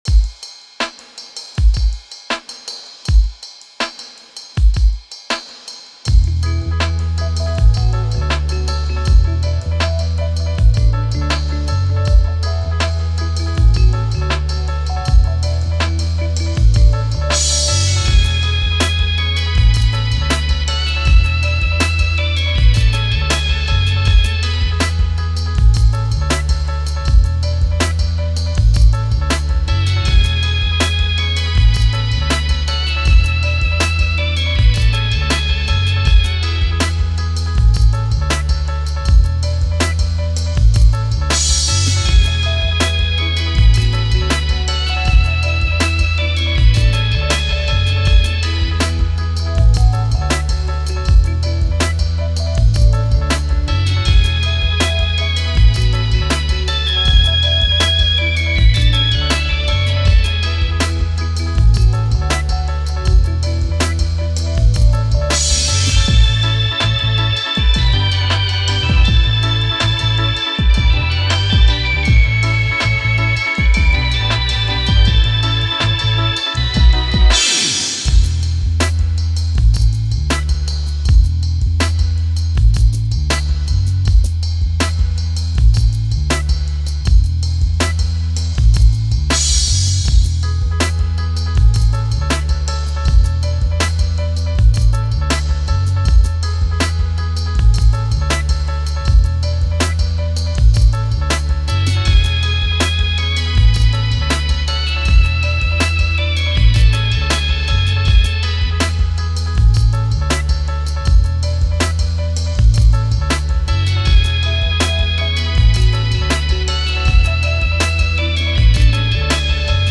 Vintage journeys into Dub.      mp3